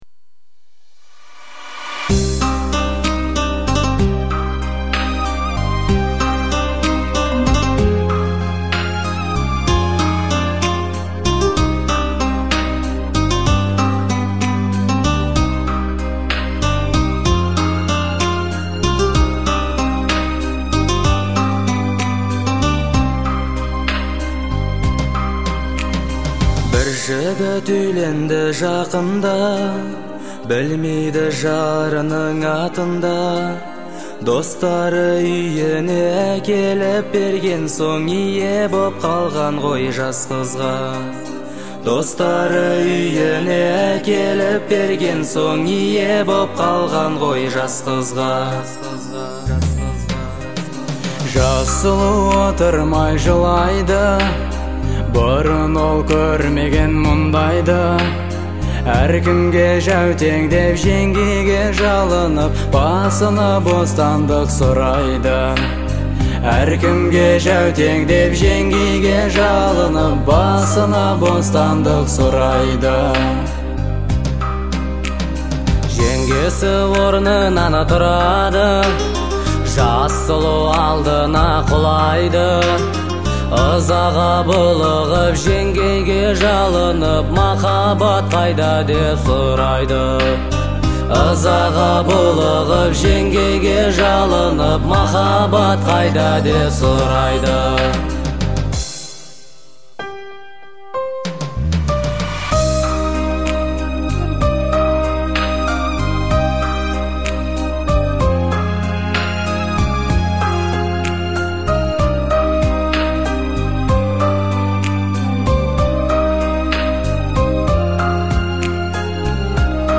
это искренний и трогательный трек в жанре поп